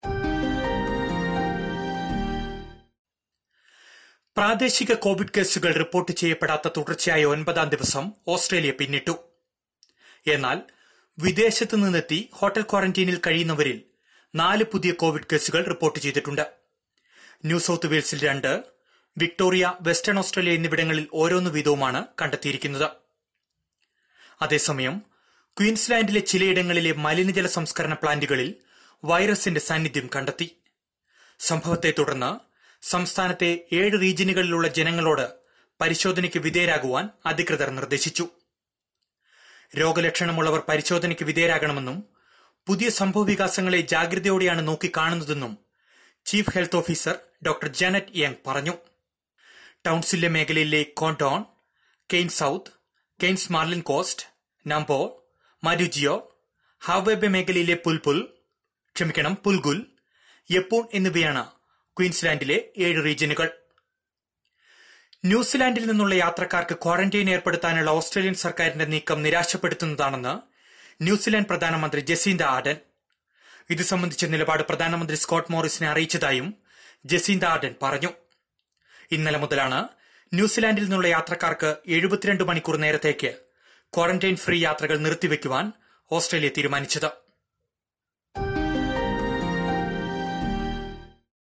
SBS Malayalam COVID-19 news update.